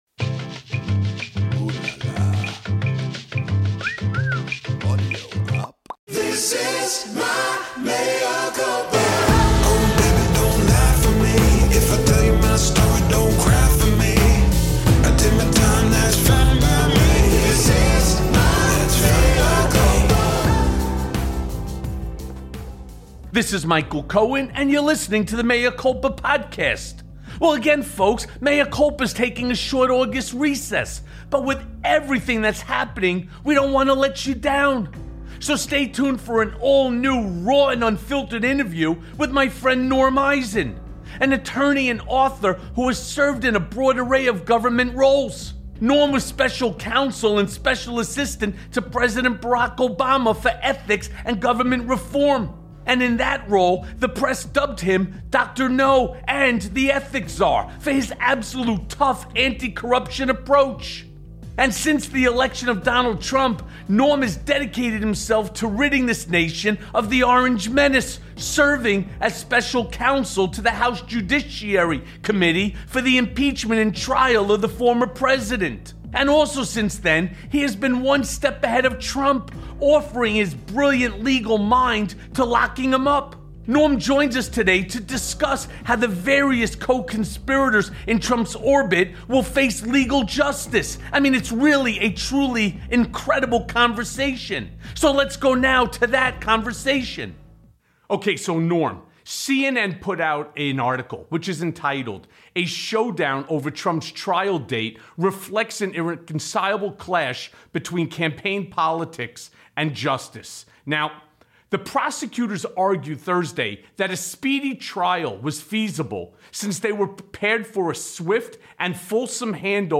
Will Trump Get A Mugshot? + A Conversation with Norm Eisen
So, stay tuned for an all-new interview with Norm Eisen.